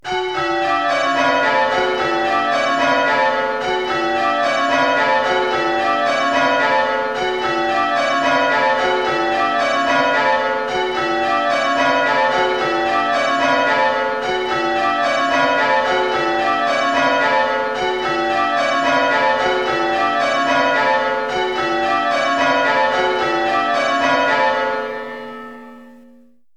6 Bells Test This is an audio test to see if you are able to spot the poor striking. First we will play an audio track, which is defined as 'Perfectly struck rounds on 6 bells at Coggeshall' When are able to hear the audio and recognise the ringing rhythm, press Continue.
bells6a.mp3